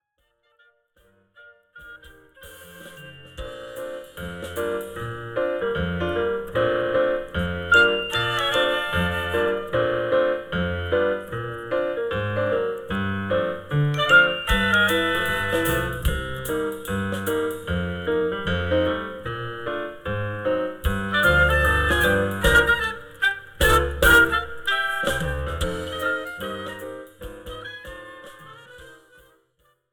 This is an instrumental backing track cover.
• Without Backing Vocals
• No Fade
Backing Tracks for Karaoke, Accompaniment.